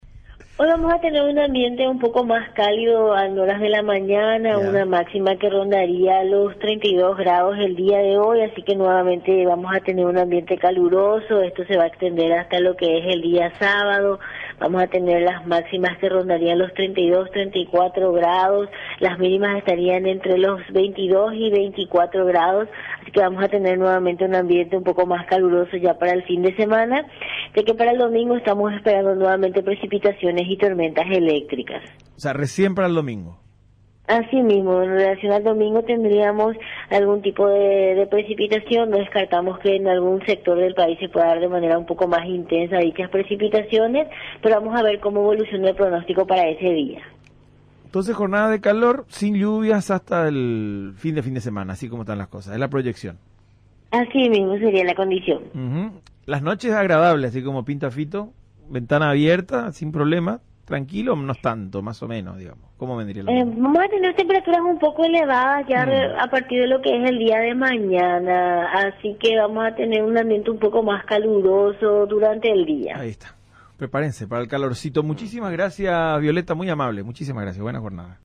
pronosticadora de turno